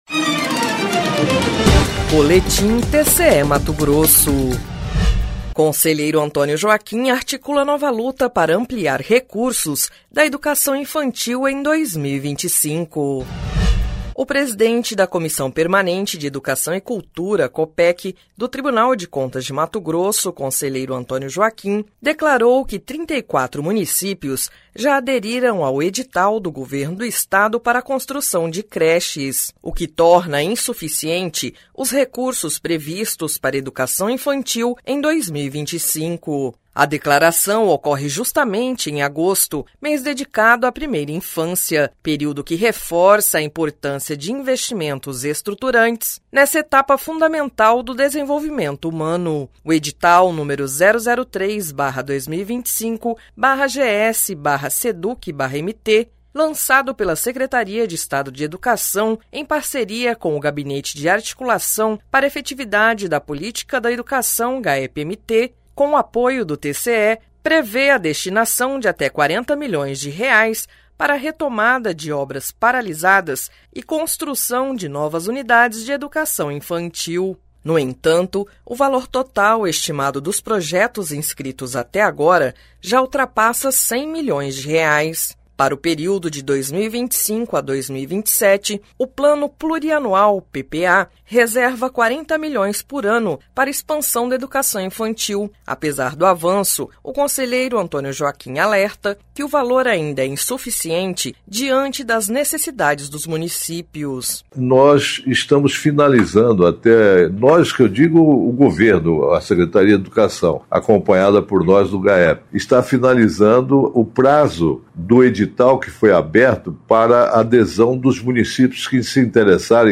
Sonora: Antonio Joaquim – conselheiro presidente da COPEC-TCE/MT e membro do Gaepe-MT